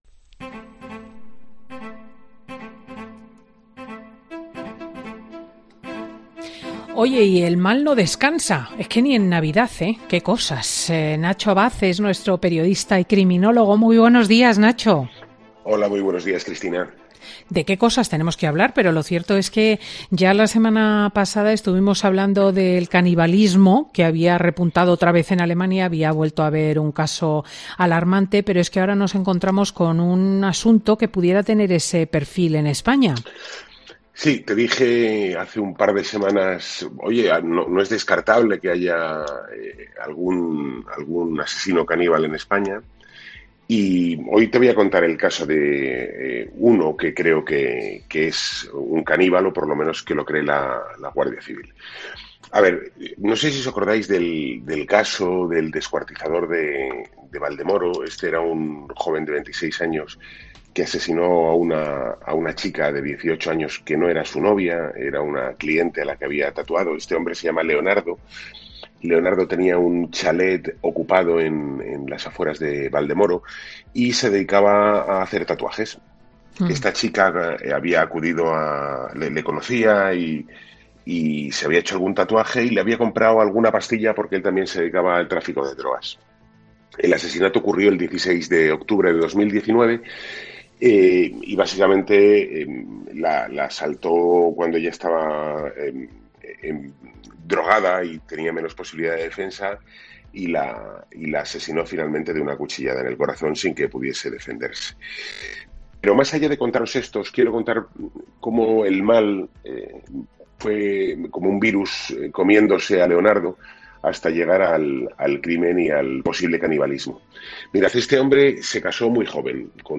El periodista especializado en sucesos cuenta en Fin de Semana con Cristina cómo este caso hace pensar en los grandes asesinos de la historia, incluso...